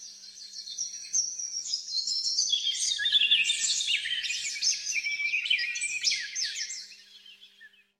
Forest Morning
Early morning forest ambience with birdsong, gentle wind through leaves, and distant stream
forest-morning.mp3